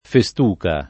festuca [ fe S t 2 ka ] s. f.